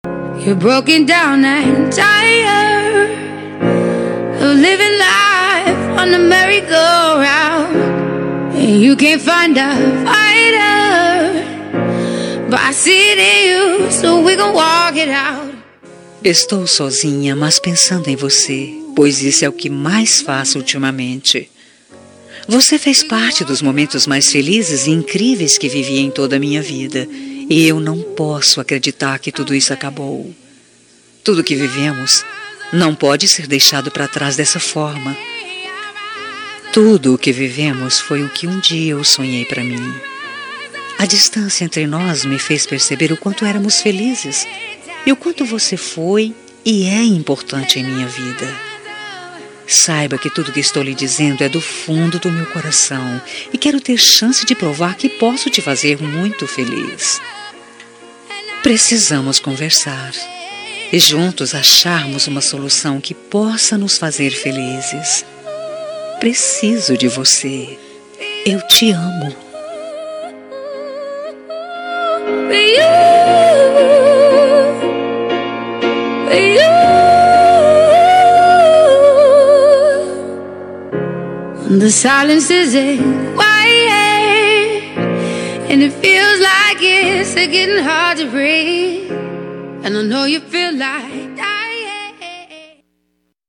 Telemensagem de Reconciliação Romântica – Voz Feminina – Cód: 202084